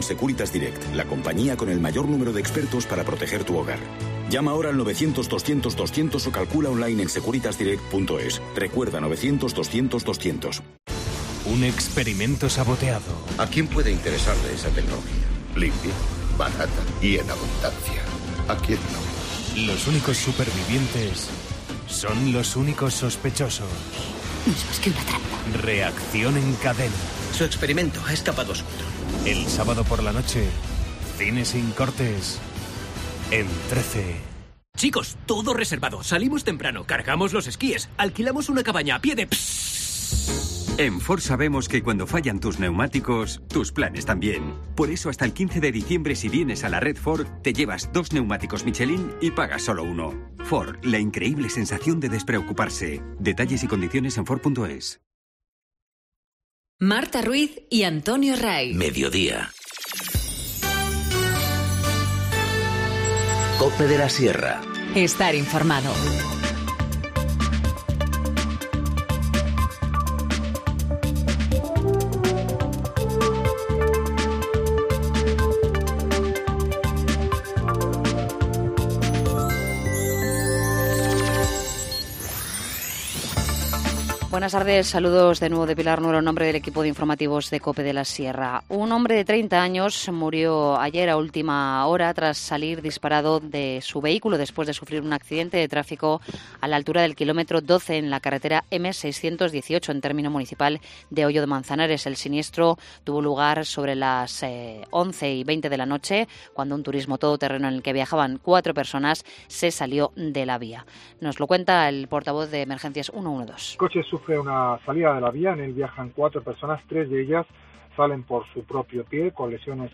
Informativo Mediodía 27 nov- 14:50h